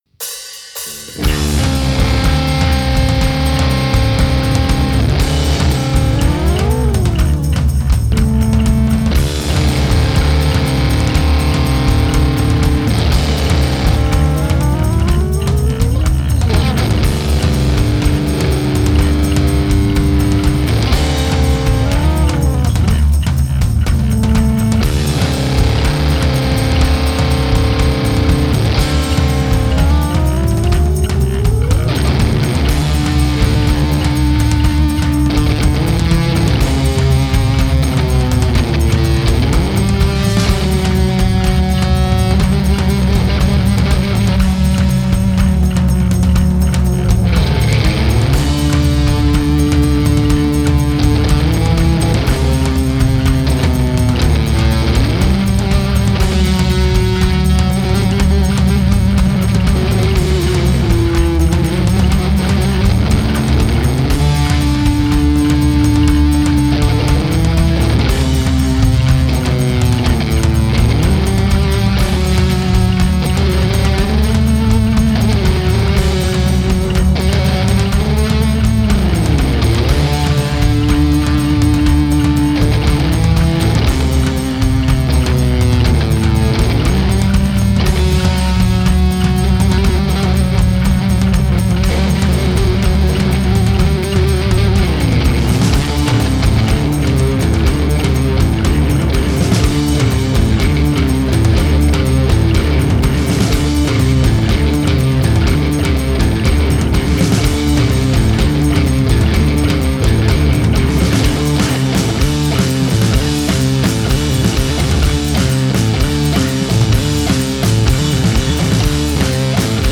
Жанр: Rock.